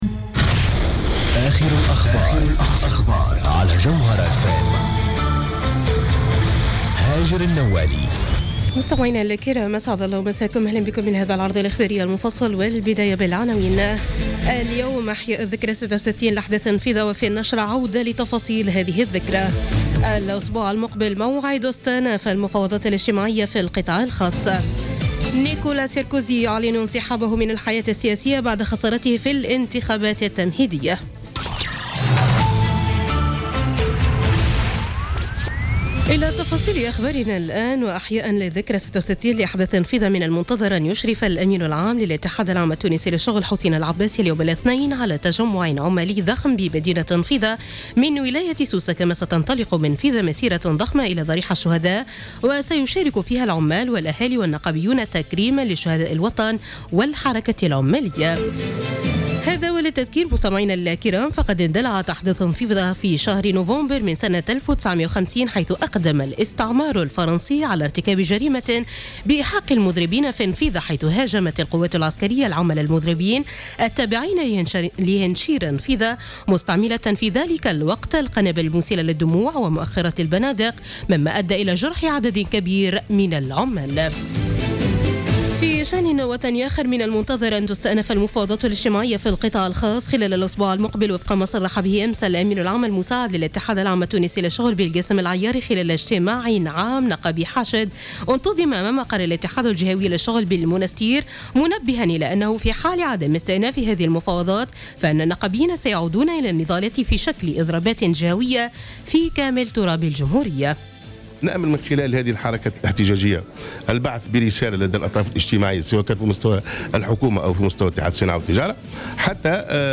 نشرة أخبار منتصف الليل ليوم الإثنين 21 نوفمبر 2016